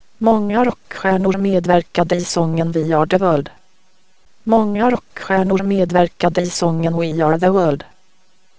Speech synthesis example.